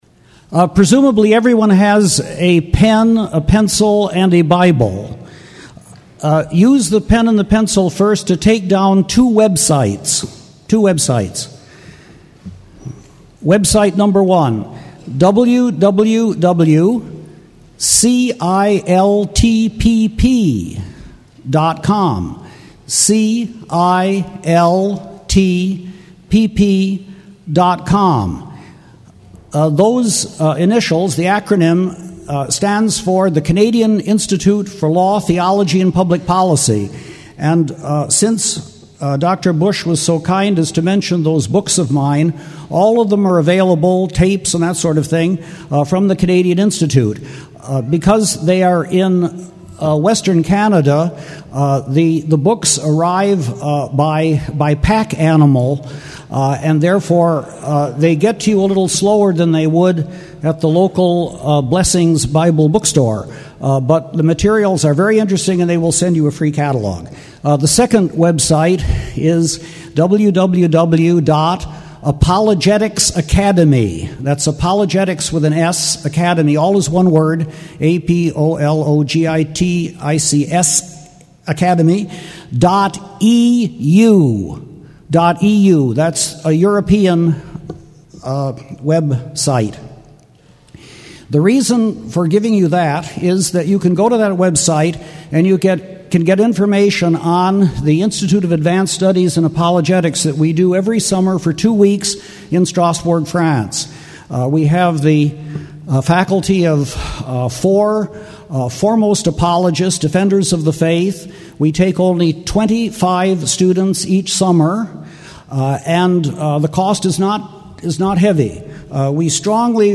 File Set | SEBTS_Chapel_John_Warwick_Montgomery_2006-11-14.wav | ID: 86e225c0-be99-4e0c-9615-1d36531c2ccf | Hyrax